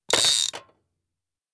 hookin.ogg